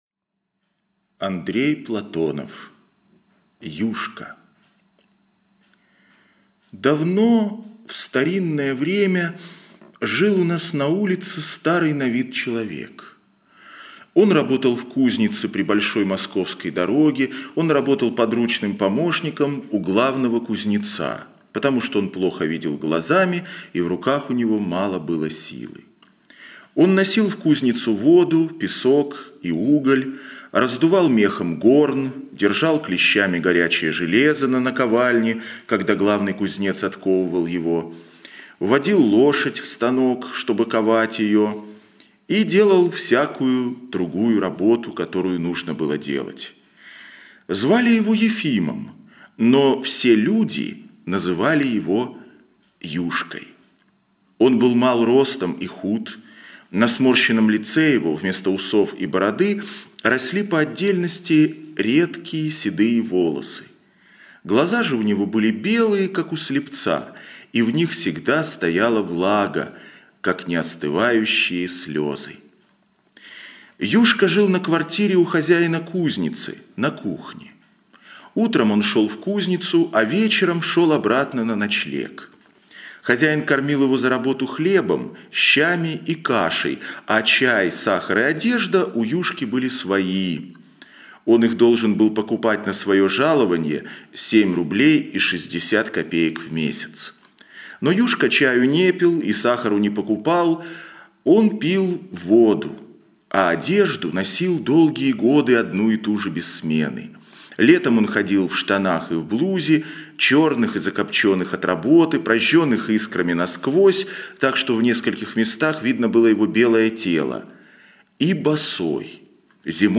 Юшка - аудио рассказ Платонова А.П. Рассказ про помощника кузнеца по прозвищу Юшка, который был болен, выглядел как старик...